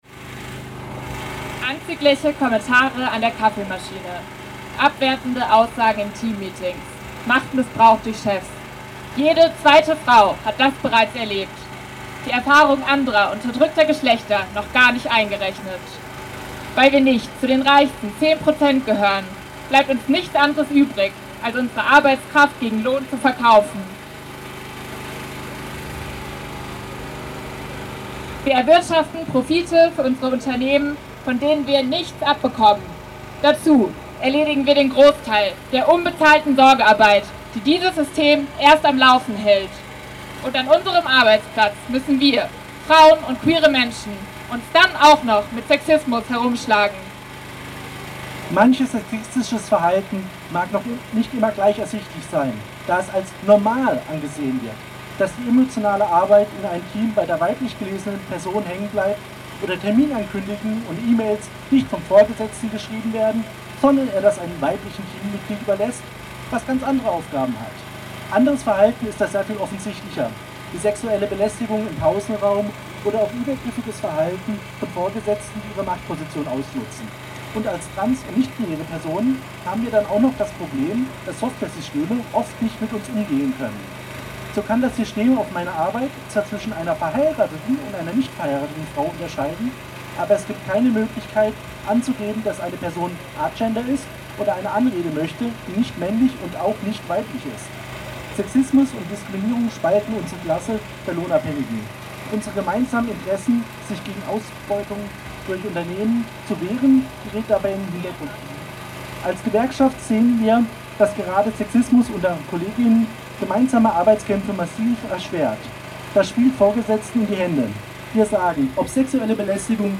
In den frühen Abenstunden, des 8. März 2024, demonstrierten in Freiburg mehrere Tausend Menschen für Geschlechtergerechtigkeit und gegen Sexismus und Patriarchat.